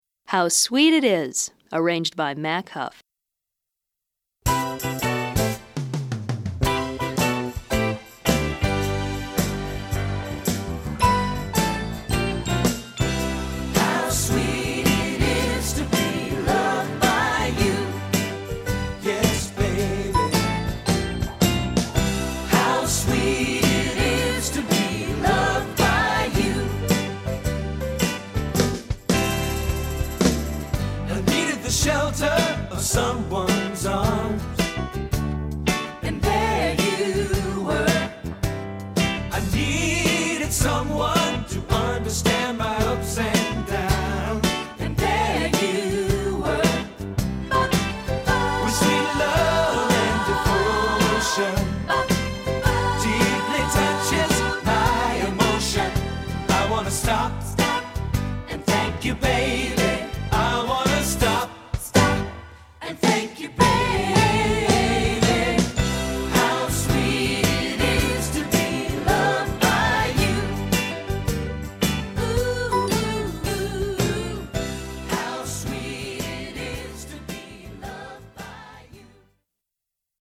Choeur (Unisson), Chant Hautes et Chant Egales